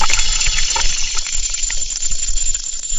Potion Bubble
A mysterious potion bubbling in a cauldron with thick, gloopy pops and steam hiss
potion-bubble.mp3